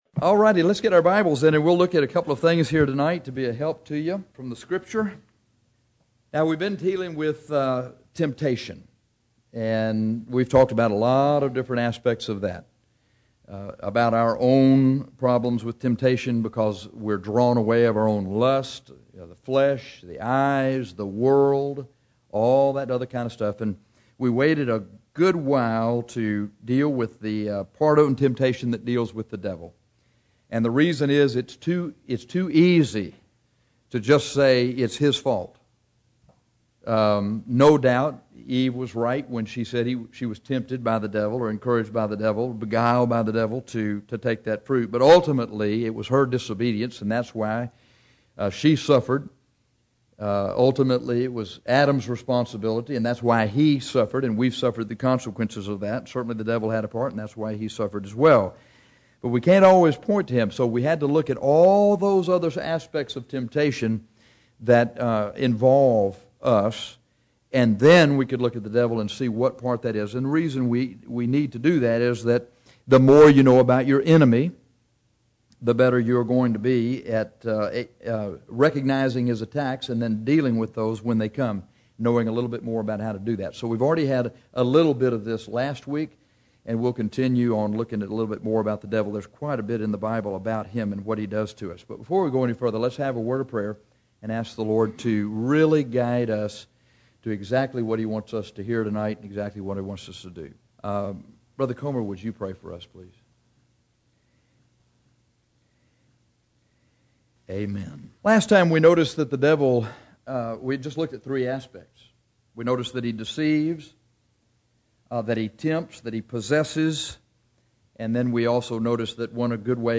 In this sermon we examine how he condemns, ensnares and opposes Christians.